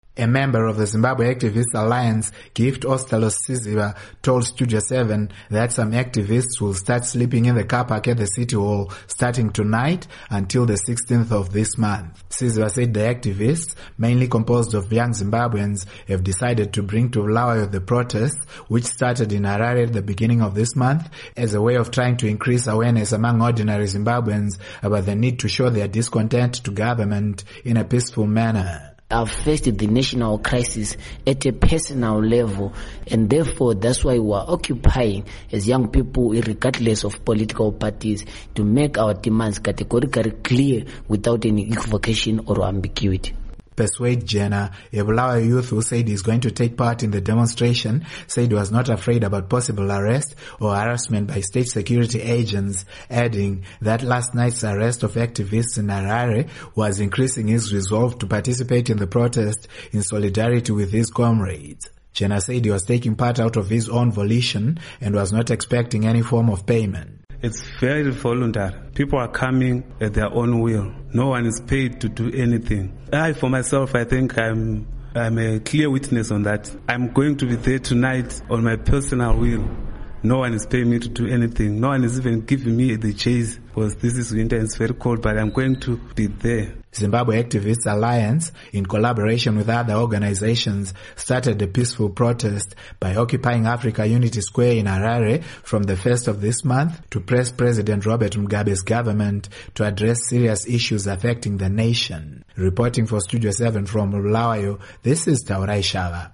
Report on Occupy Bulawayo